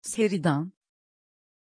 Pronunciation of Sheridan
pronunciation-sheridan-tr.mp3